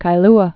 (kī-lə)